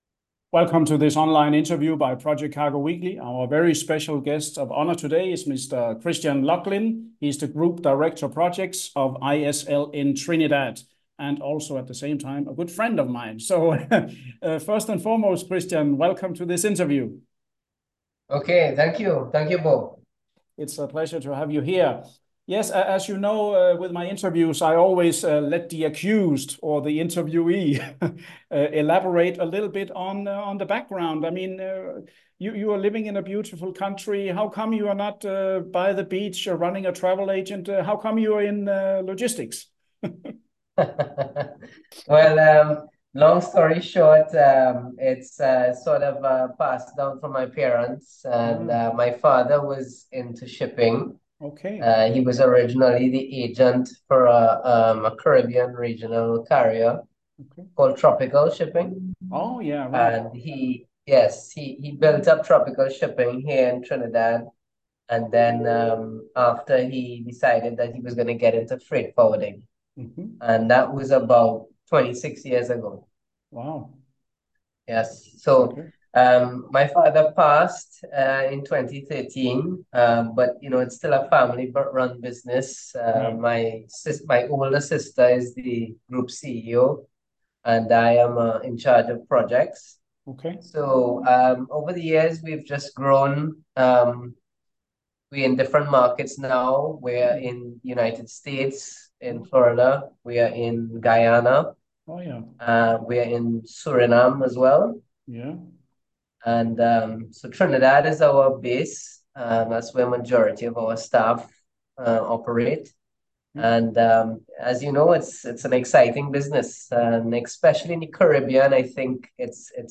Video InterviewISL – Trinidad